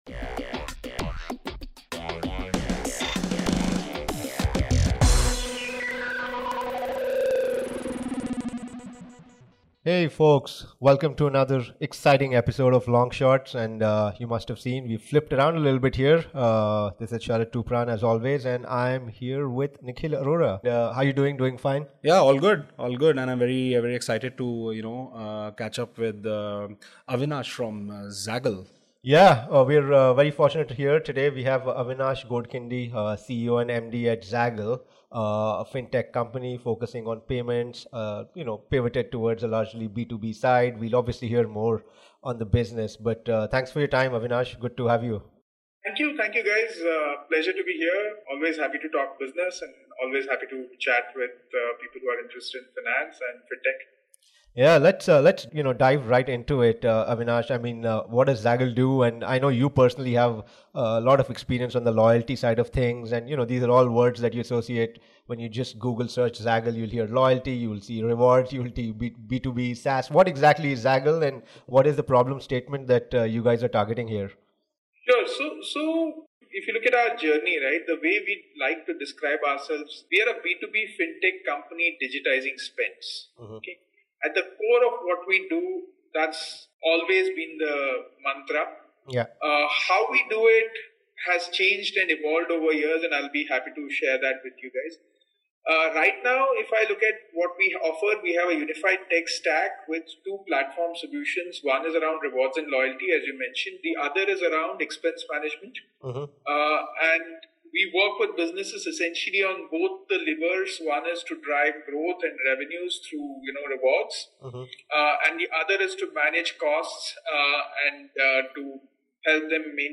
We started with a conversation on the evolution of his business, but quickly segued into a wider chat on the contemporary evolution and challenges within fintech.